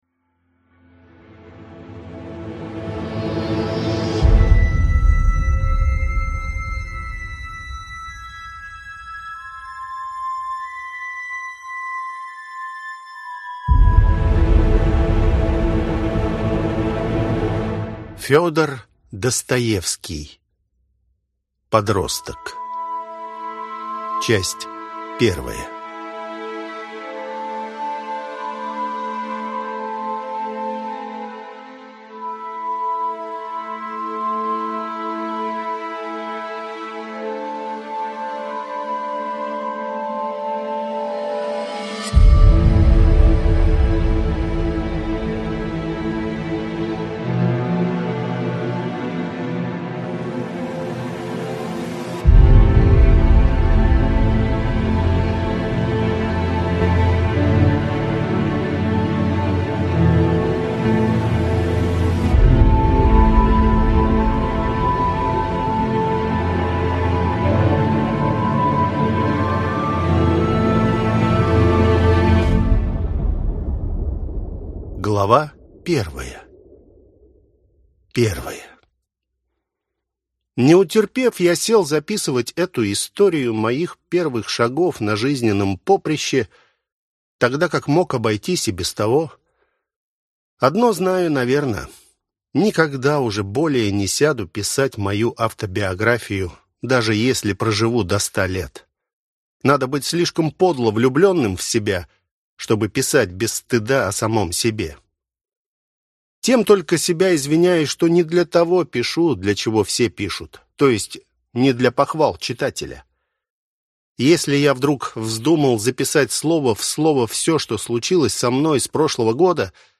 Аудиокнига Подросток